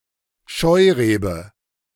Scheurebe (German pronunciation: [ˈʃɔɪˌʁeːbə]
De-Scheurebe.ogg.mp3